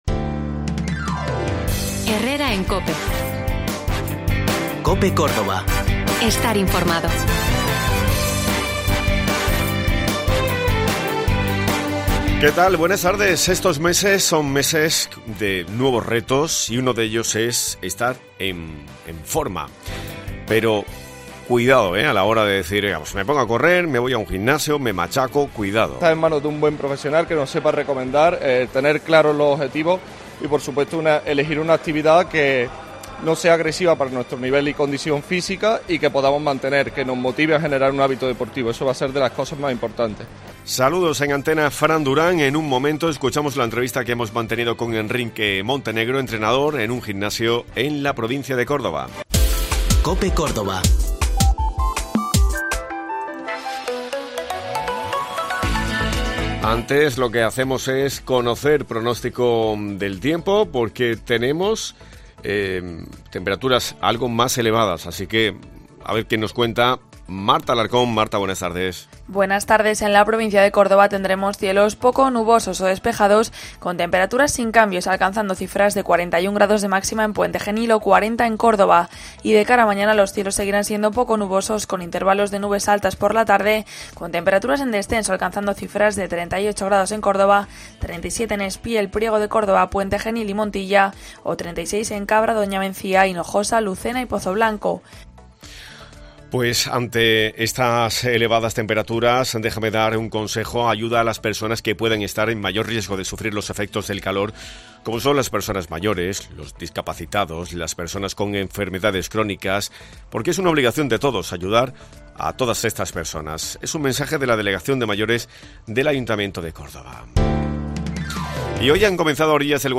Hoy nos hemos acercado por un gimnasio para conocer qué debemos tener en cuenta a la hora de comenzar una actividad deportiva.